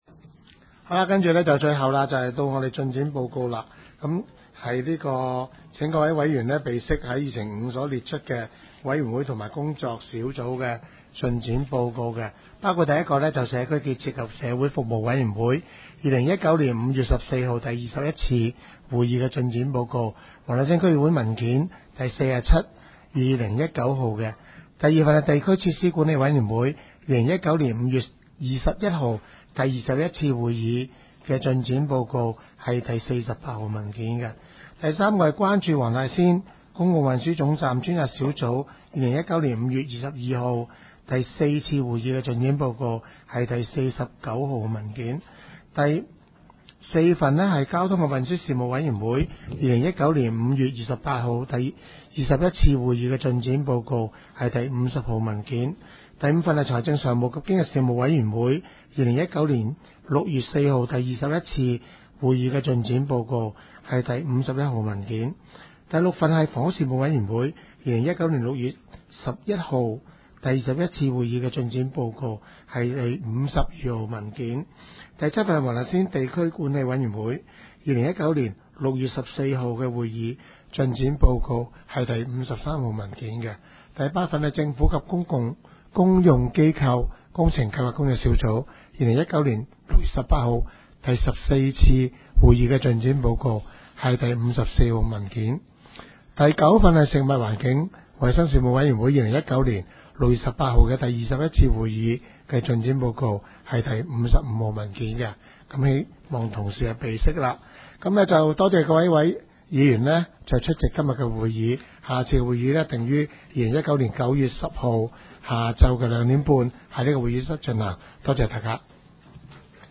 区议会大会的录音记录
黄大仙区议会第二十三次会议
黄大仙区议会会议室